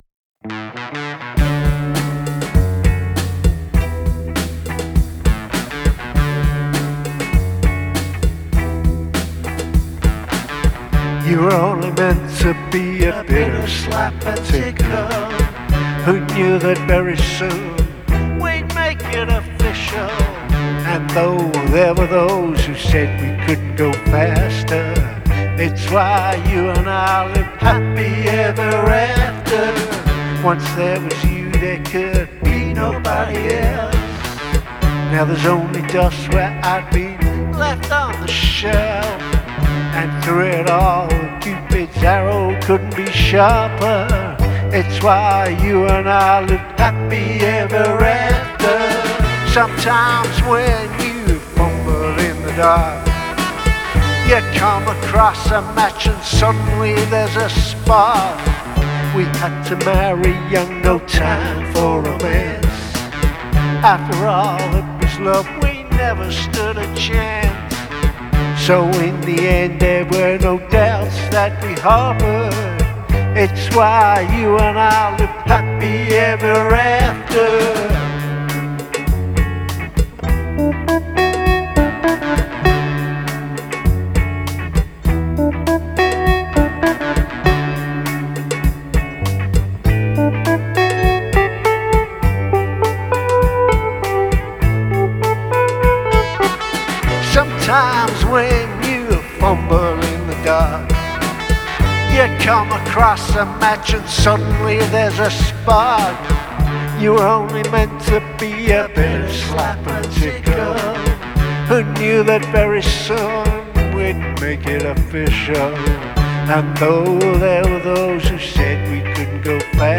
Performed by: The Quirks
The Quirks are back with more of their lounge-bar musings.
bongos
Vibes
Bazouki
Keys